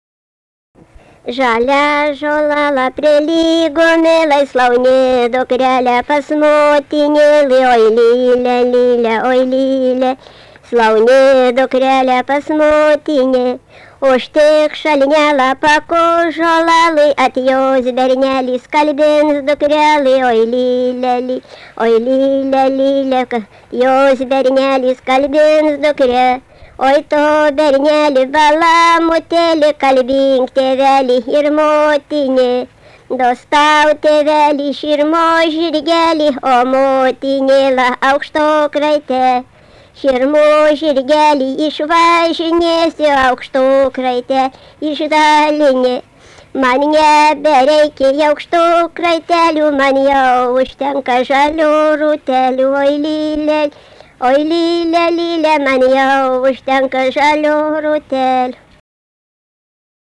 daina
Ryžiškė
vokalinis